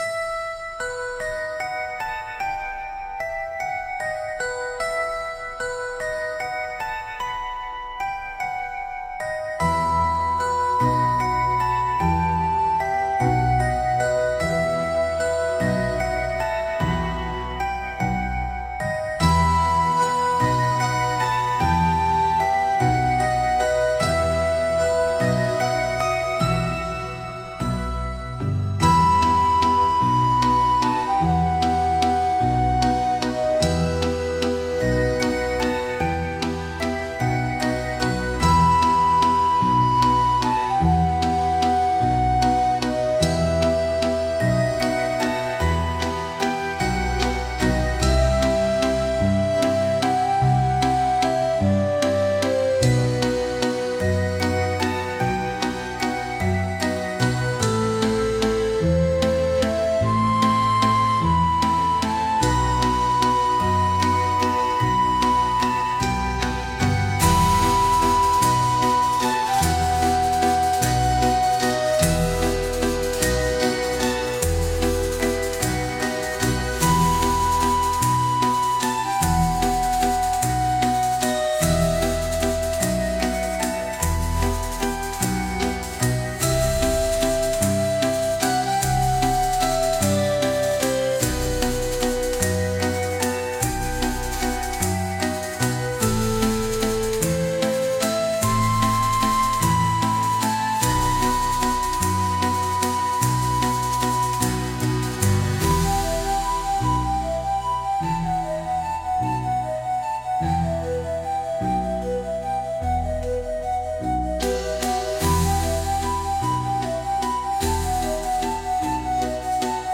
Instrumental - Real Liberty Media DOT xyz-3.11